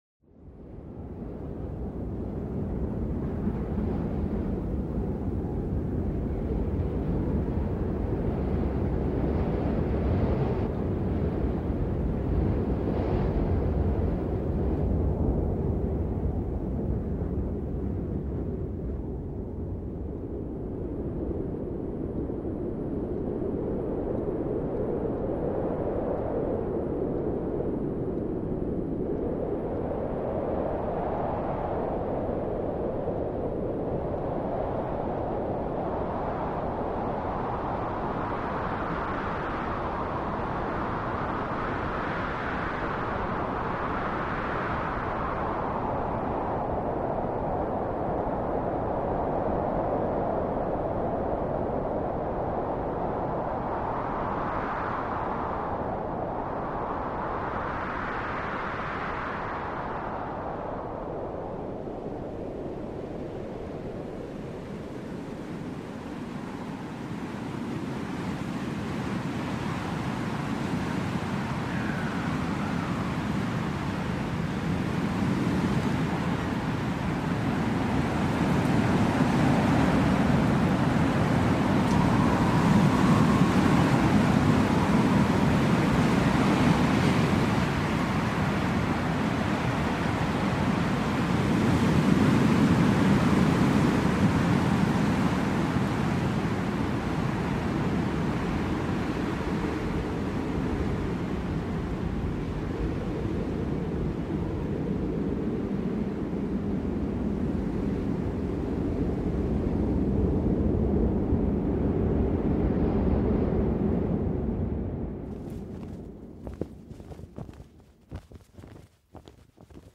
This design transforms the layered sounds of wind into a visual expression of motion and mood, drawn from real audio textures: the howl of a gale, the brush of a breeze, and the rhythmic flutter of fabric in the air. From the raw power of strong winds to the delicate breath of light gusts and the subtle flapping of a flag caught in a breeze, this shirt tells a story of stillness, movement, and everything in between.
vento_mixagem.mp3